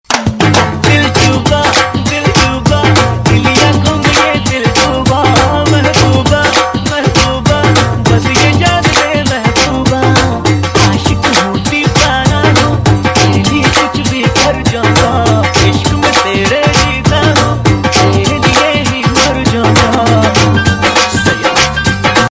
Navratri Ringtones